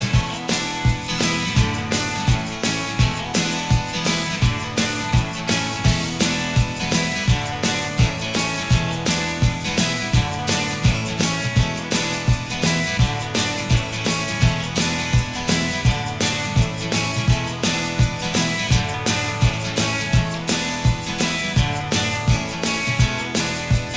Minus Bass Guitar Rock 3:37 Buy £1.50